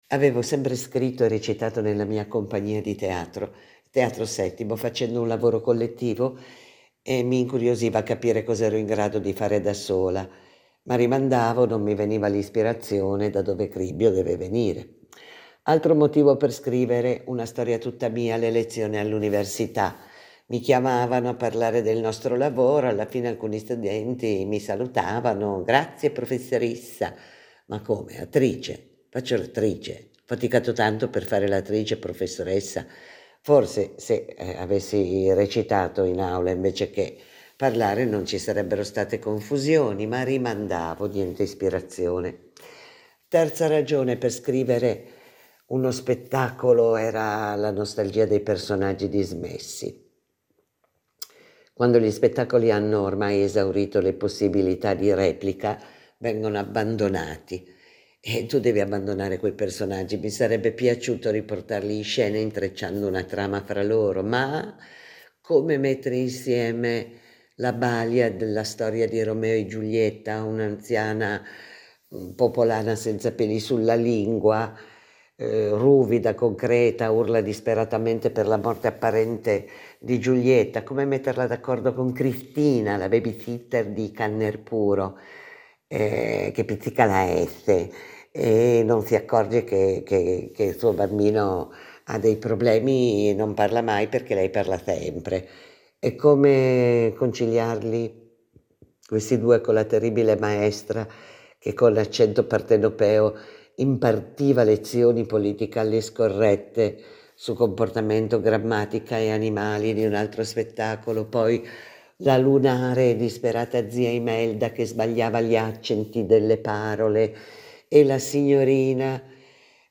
Sarà lei ad accompagnarci ogni giorno alle 18, attraverso cinque momenti importanti della sua vita. Letture, spettacoli esperienze che hanno fatto la sua storia di autrice, interprete e testimone.